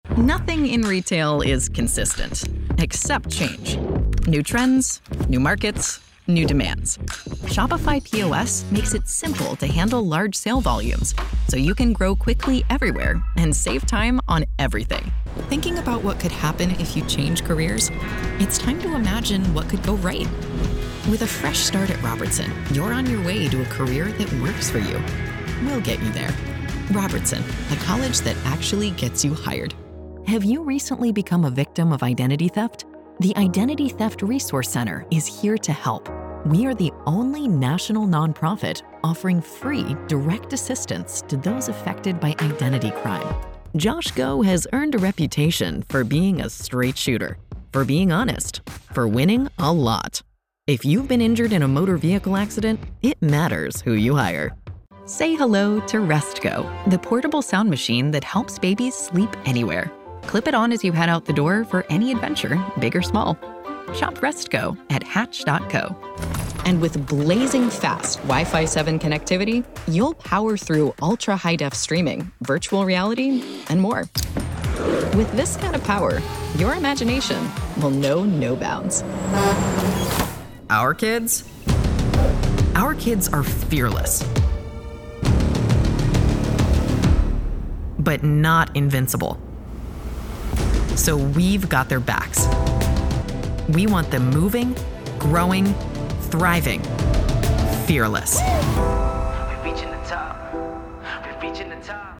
Female Voice Over Talent
Friendly, Warm, Conversational.
Commercial